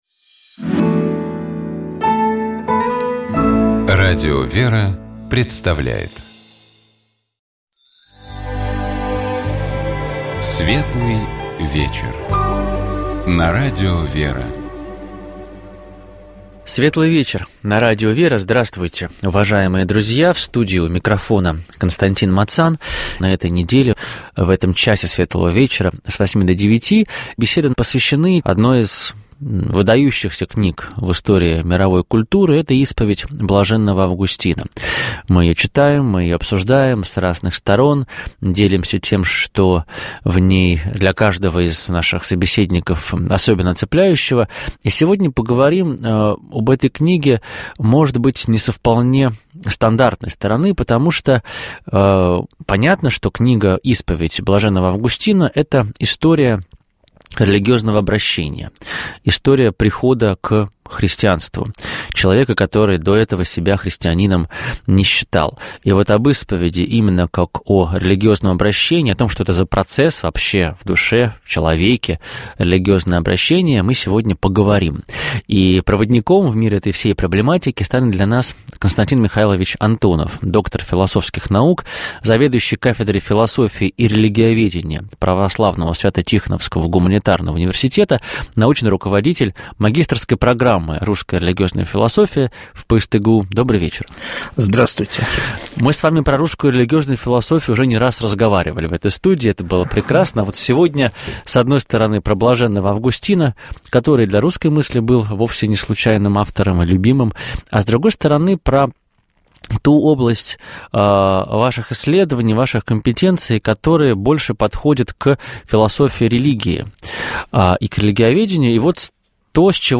У нас в гостях насельник